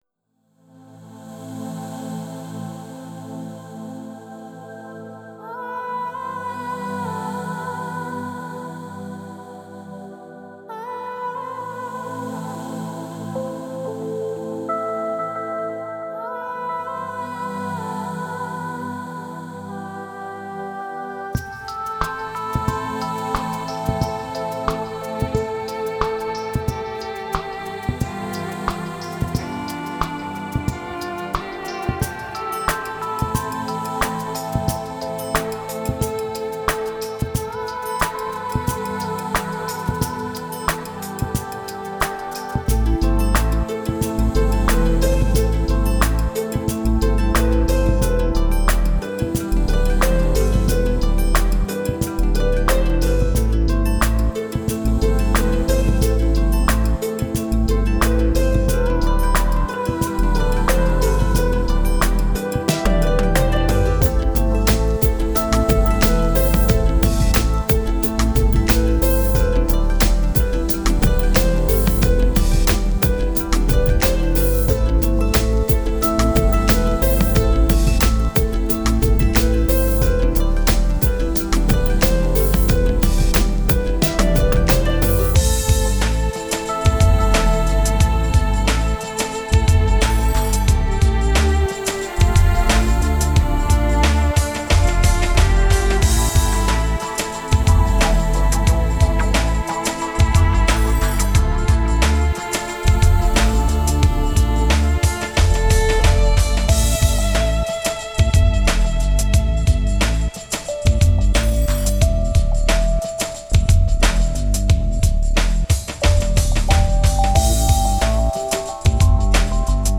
Genre: New Age, Chillout, Celtic